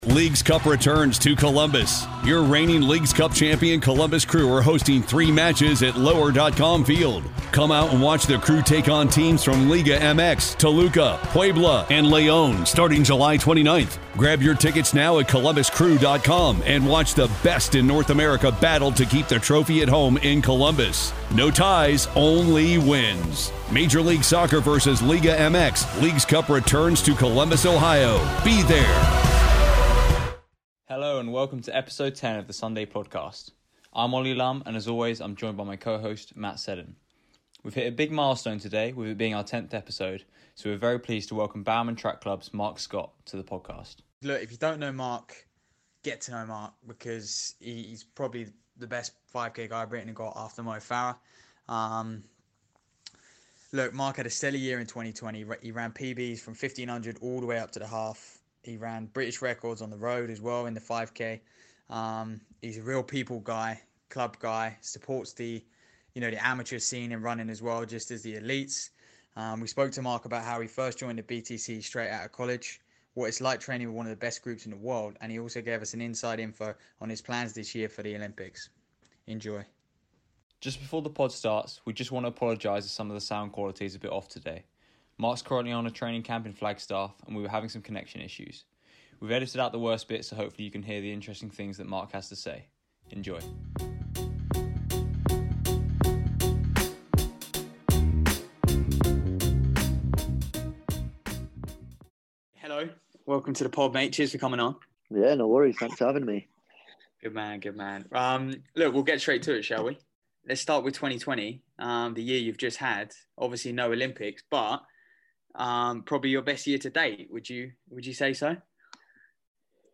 For our 10th episode, we spoke to Bowerman Track Club's, Marc Scott. We spoke to Marc about his fantastic year in 2020, his experiences training with the guys at BTC, and his plans for the 2021 Olympics.